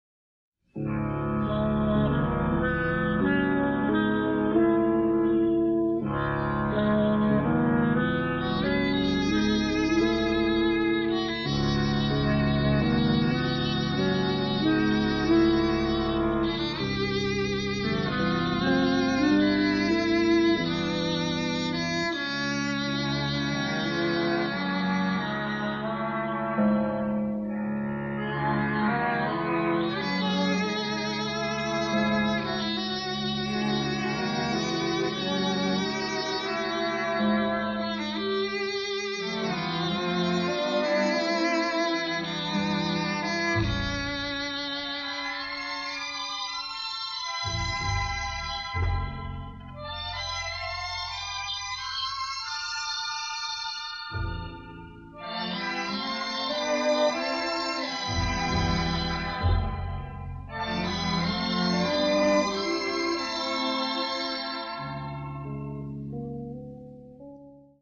innovative use of synthesizers combined with jazz elements